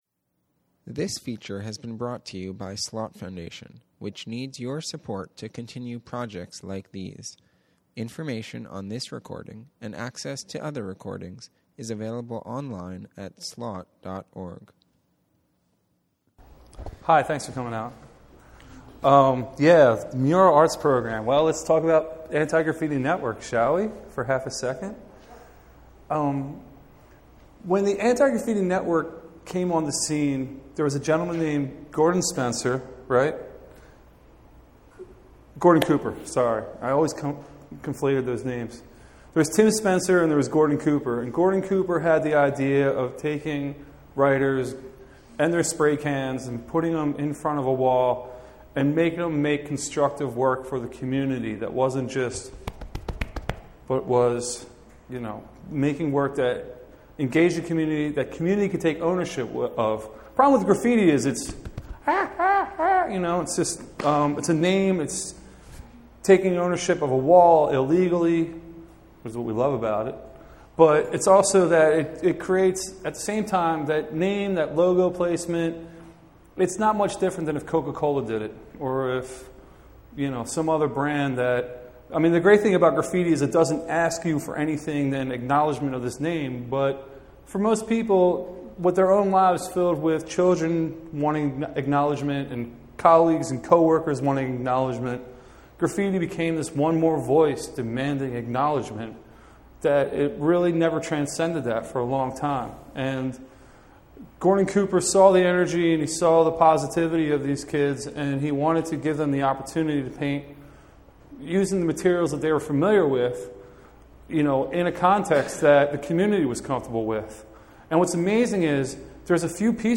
Slought is pleased to announce "Love Letter," a conversation with artist Steve Powers on Wednesday, September 23, 2009 from 7-9pm. "Love Letter" is literally a love letter painted on the walls facing the Market elevated train in West Philadelphia. 40 local and international artists will paint the walls in August 2009 and the "letter" will be completed September 12th.